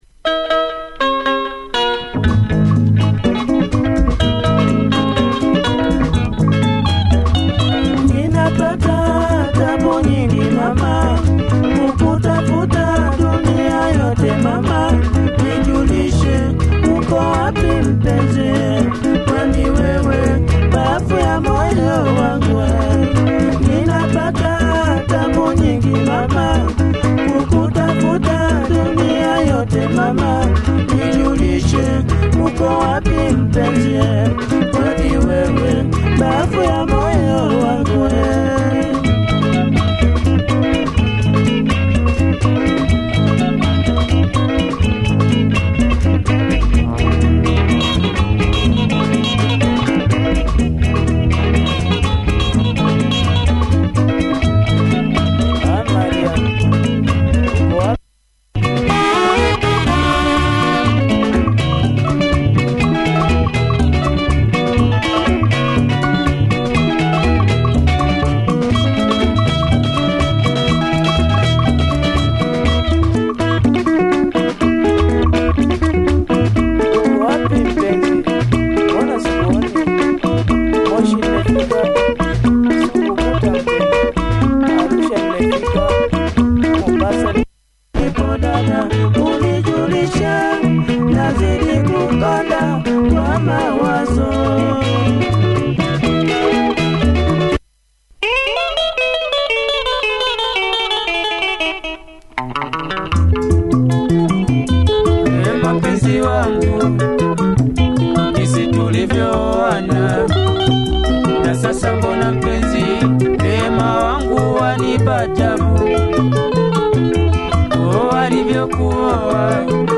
check the sax on the b-side. Clean copy! https